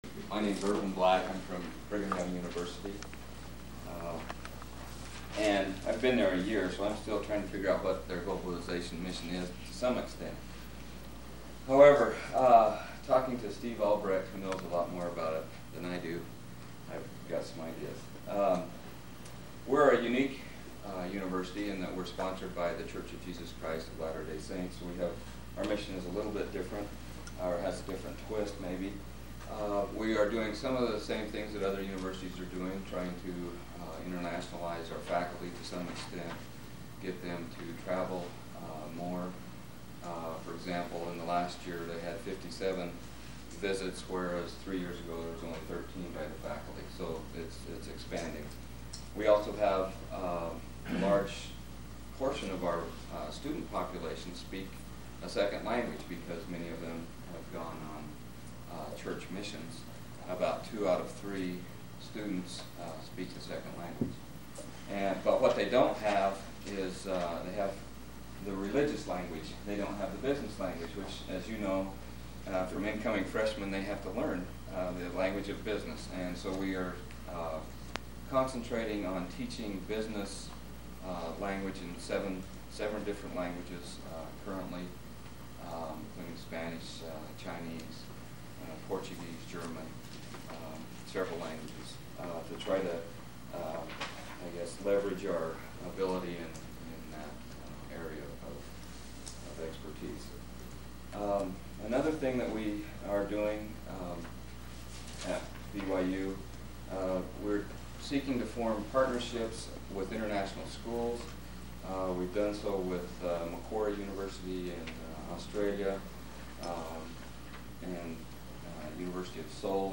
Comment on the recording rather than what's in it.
The GSAR speakers in Berlin were not wearing microphones, so the only audio captured was through the microphone built into my old Sony camcorder.